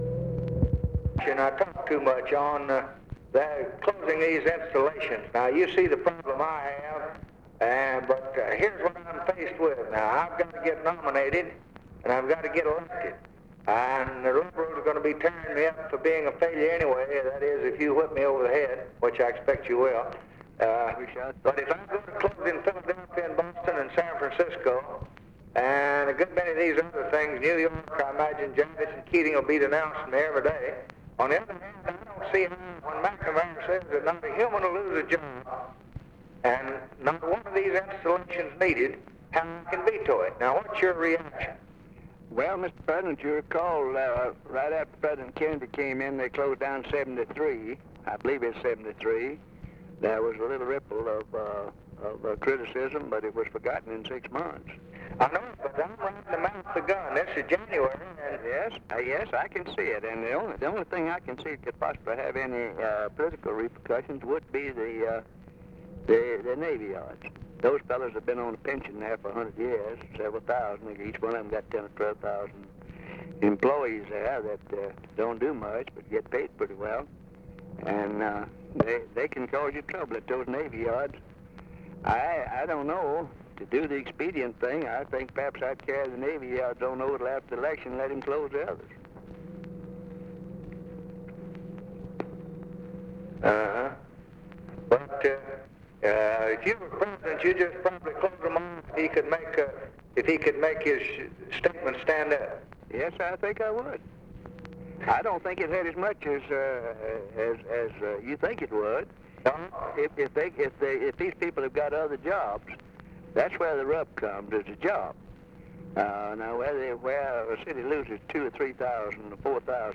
Conversation with RICHARD RUSSELL, December 7, 1963
Secret White House Tapes